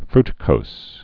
(frtĭ-kōs)